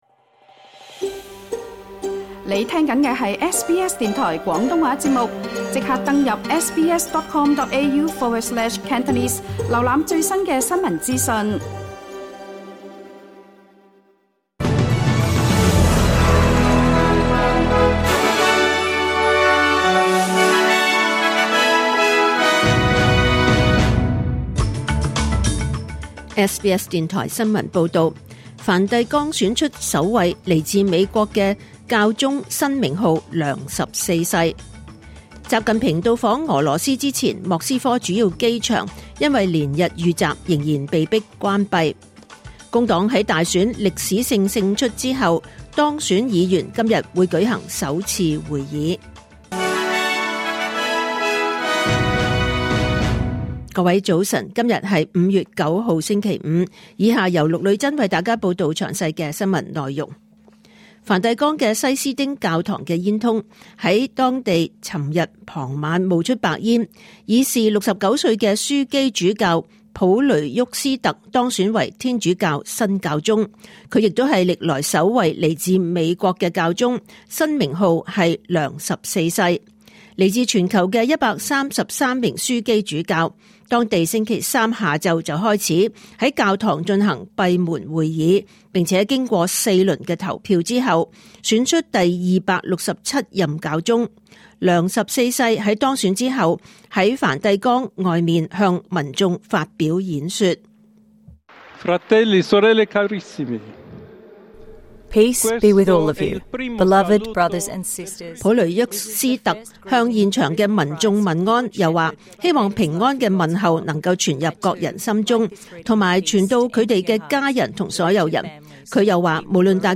2025年5月9日SBS 廣東話節目九點半新聞報道。